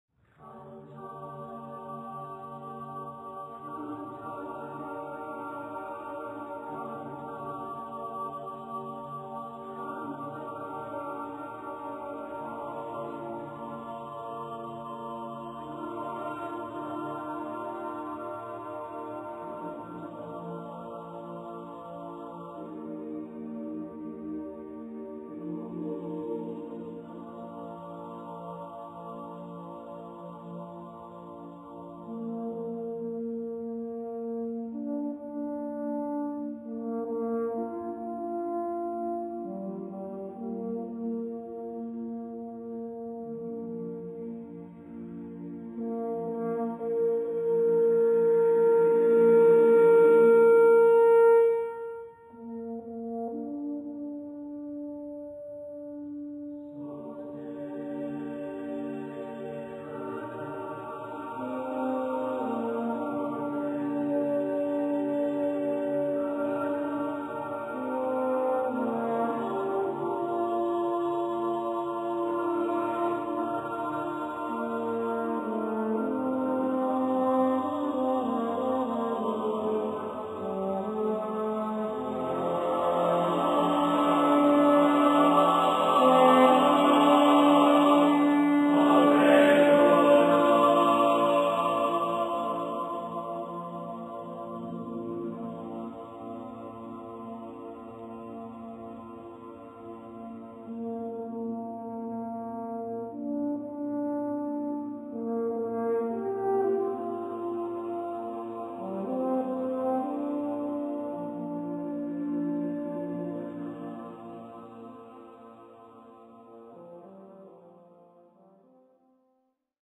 Voicing: Double SSATB choirs